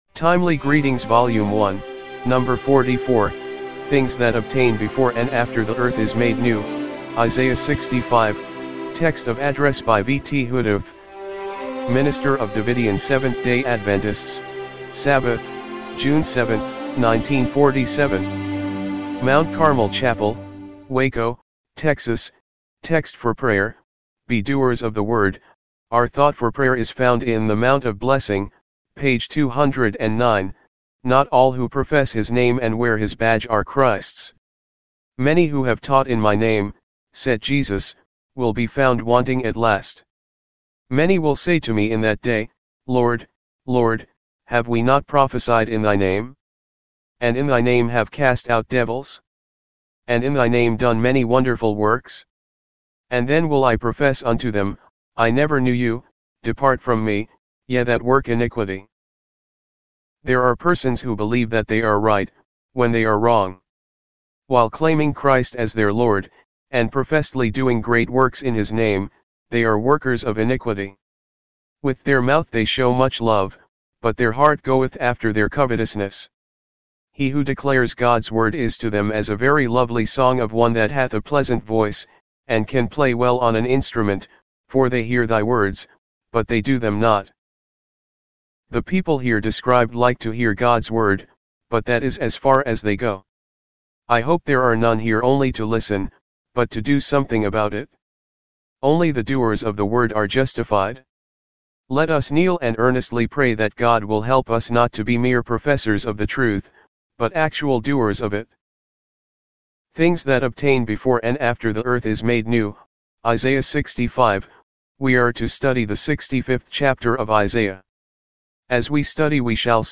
TEXT OF ADDRESS
1947 MT. CARMEL CHAPEL WACO, TEXAS